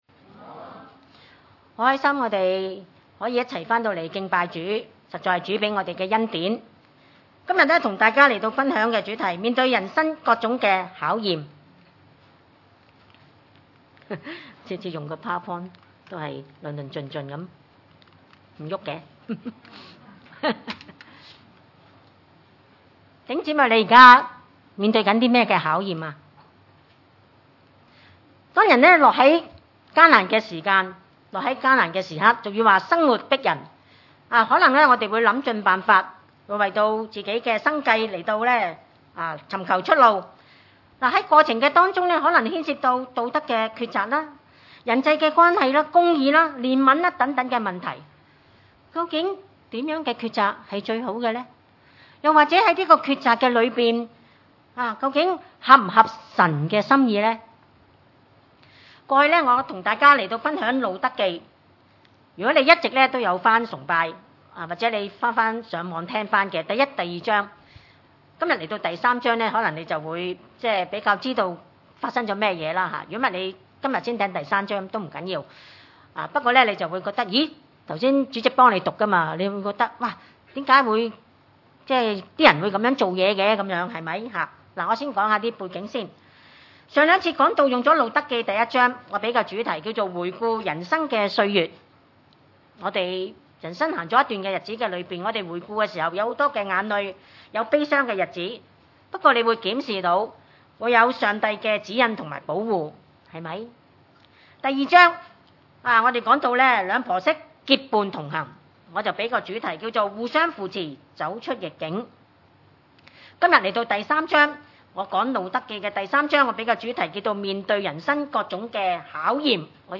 經文: 路得記 3 : 1 - 18 崇拜類別: 主日午堂崇拜 1 路得的婆婆拿俄米對他說：女兒啊，我不當為你找個安身之處，使你享福嗎？